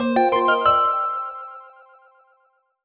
jingle_chime_04_positive.wav